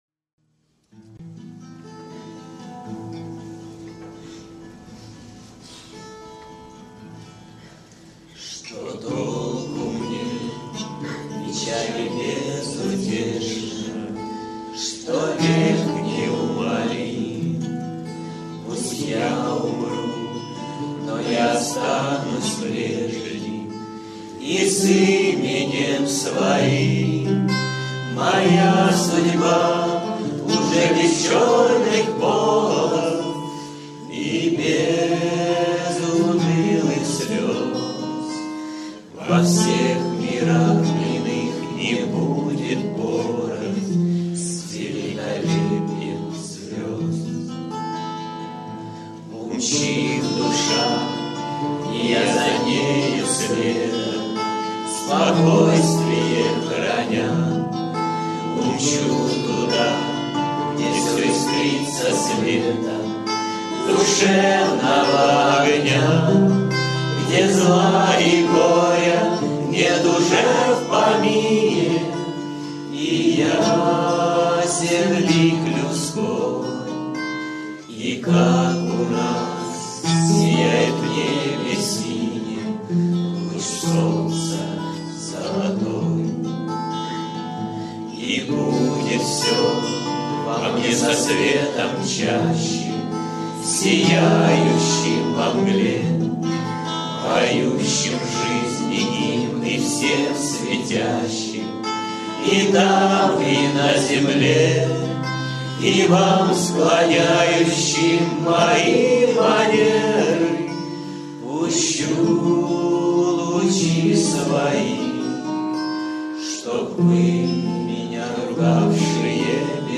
кавер-версия на мотив песни
акапелла